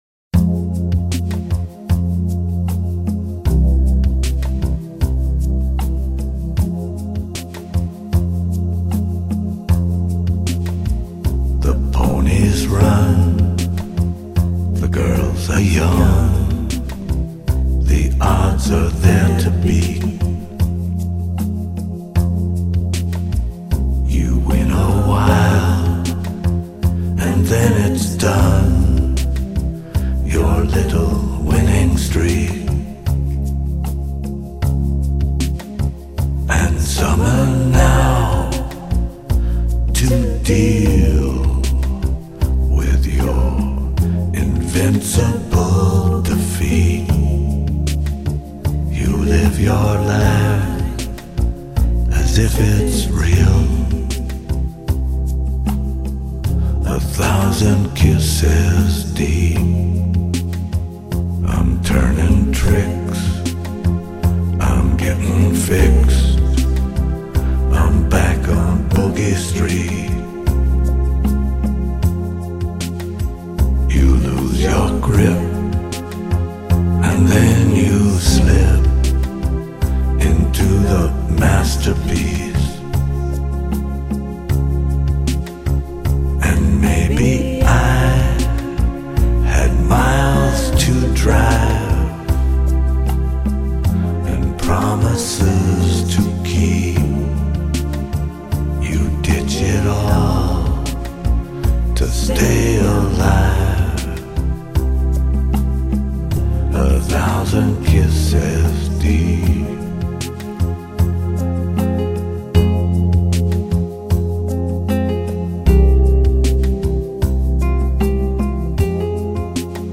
他的声音象是温柔的刀划过身体，痛着却又沉醉其中无法自拔。
歌声低沉回旋，犹如曾经的时光缓缓回流。